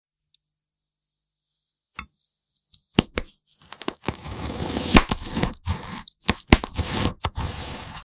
part 37 | AI ASMR sound effects free download
part 37 | AI ASMR video for cutting MERCEDES AMG GT S coupe car